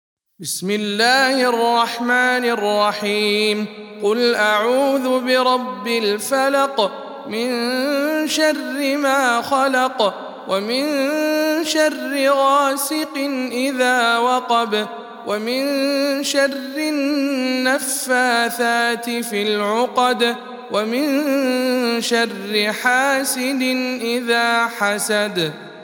سورة الفلق _ رواية ابن جماز عن أبي جعفر